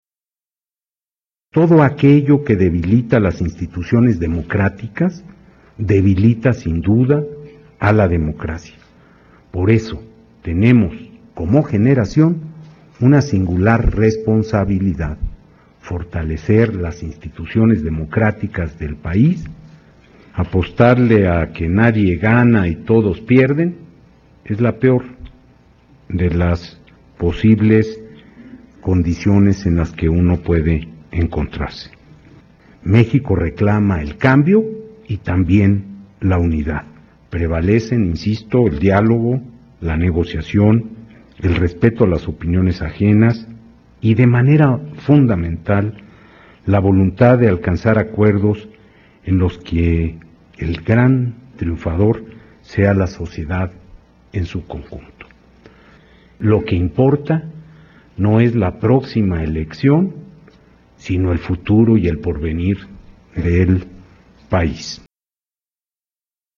México reclama un cambio en la unidad y tenemos que apelar al diálogo y a la negociación con pleno respeto a las opiniones ajenas para alcanzar los acuerdos que espera la sociedad; establecer que lo que importa no es la próxima elección, sino el futuro del país, dijo en la ceremonia inaugural del Seminario La reforma del Estado: un diálogo universitario.